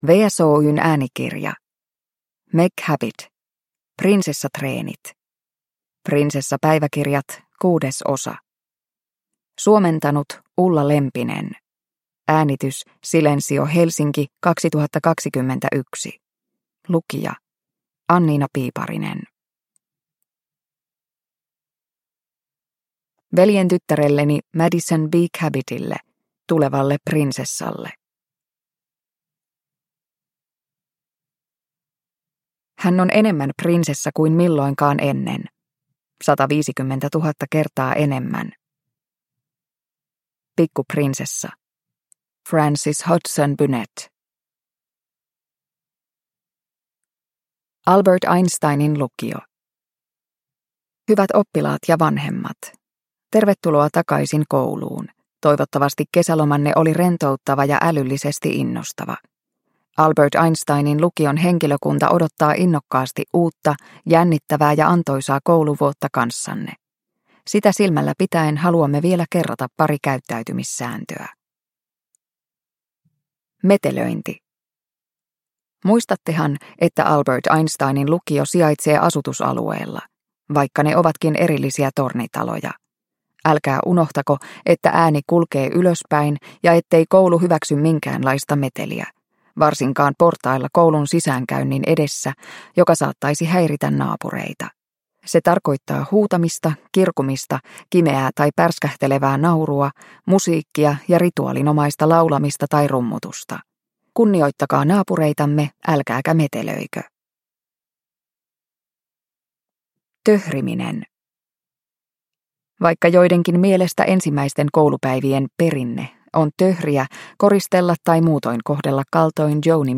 Prinsessatreenit – Ljudbok – Laddas ner